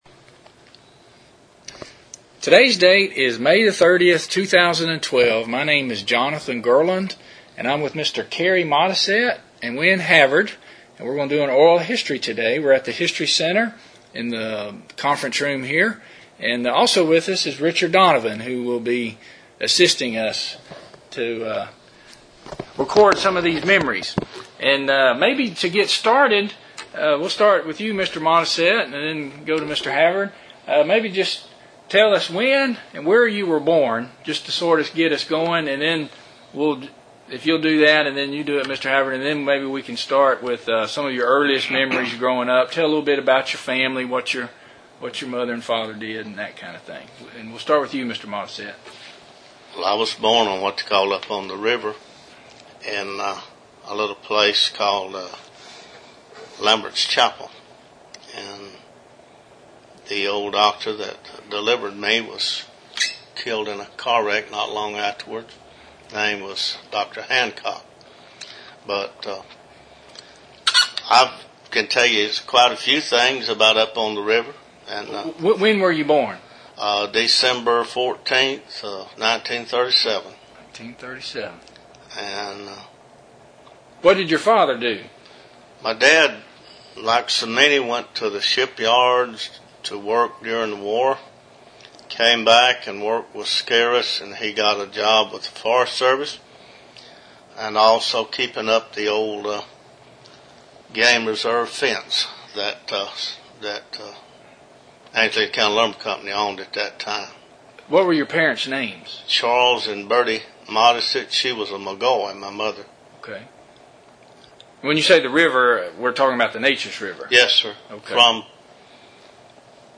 Interview 252a